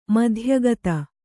♪ madhya gata